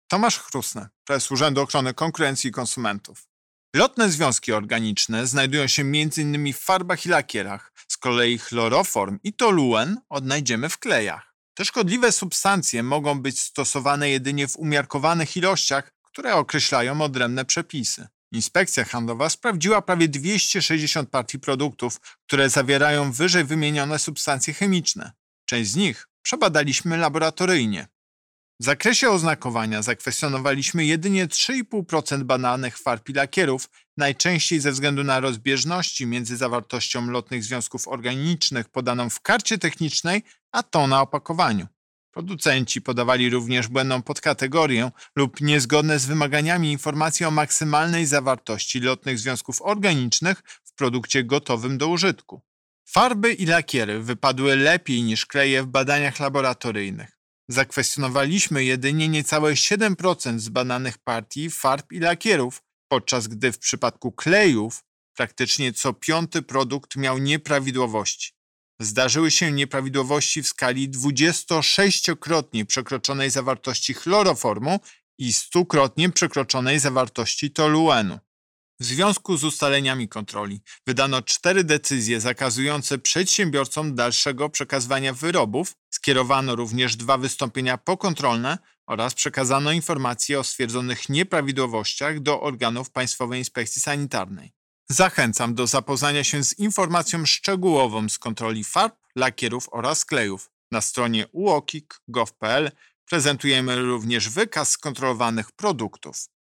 Wypowiedź Prezesa UOKiK Tomasza Chróstnego z 5 sierpnia 2021 r..mp3